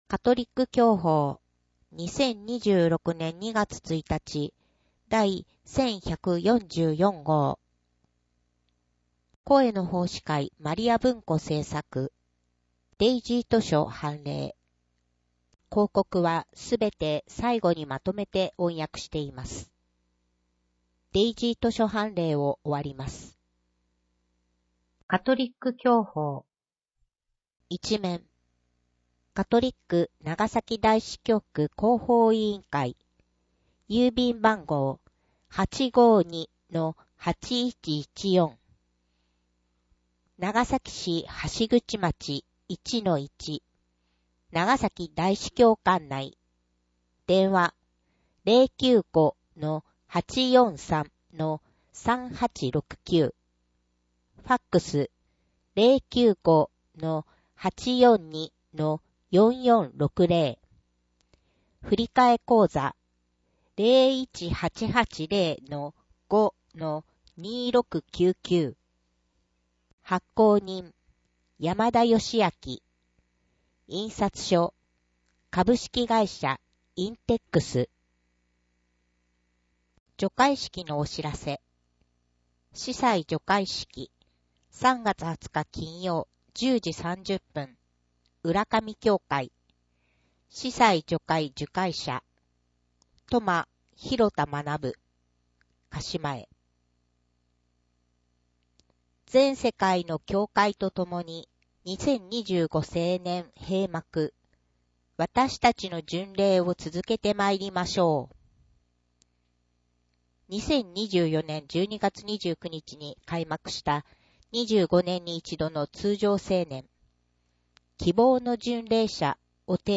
【音声訳】2026年3月号